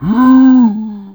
c_zombim1_atk1.wav